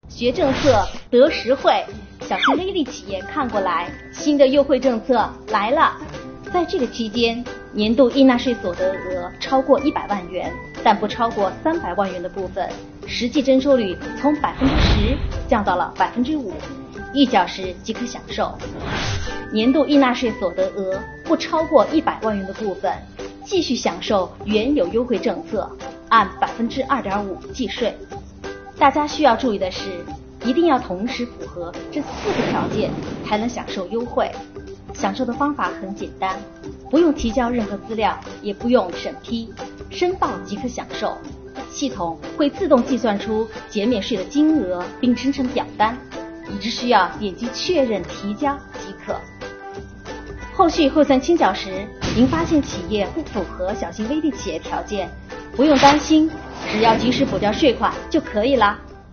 重庆市税务局推出“税惠推广人”系列短视频，业务处室负责人作为“税惠推广人”，讲解政策要点、操作流程，在90秒内尽可能让您一看就懂，一学就会，应享尽享税惠红利。